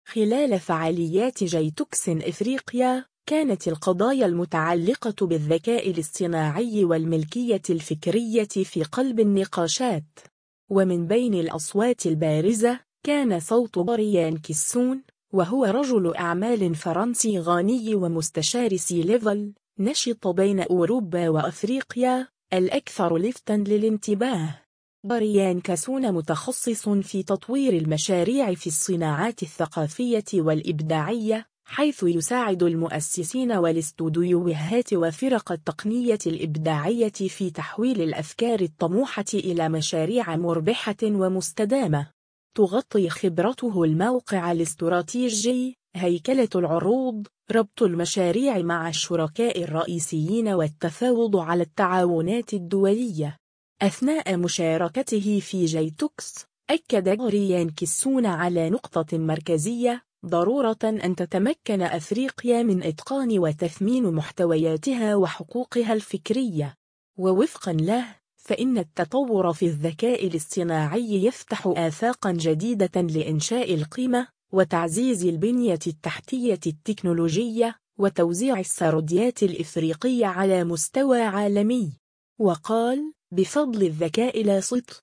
خلال فعاليات جيتكس افريقيا، كانت القضايا المتعلقة بالذكاء الاصطناعي والملكية الفكرية في قلب النقاشات.